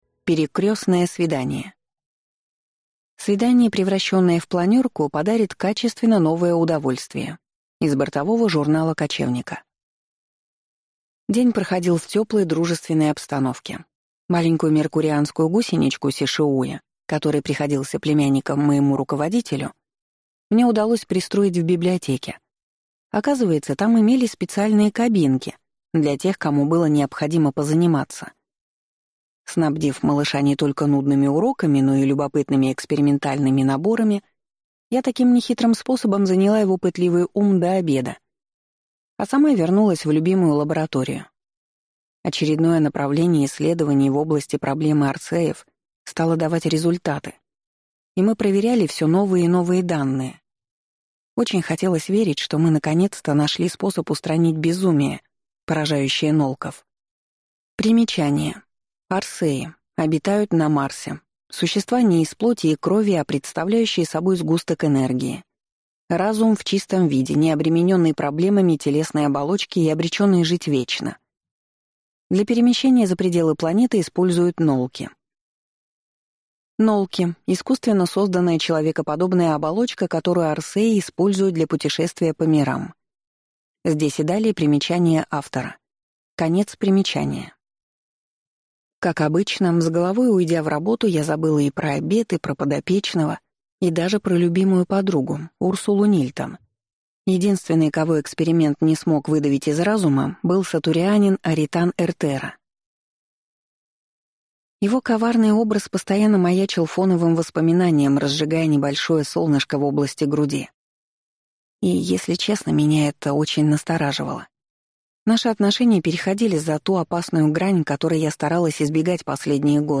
Аудиокнига Хроники одной любви | Библиотека аудиокниг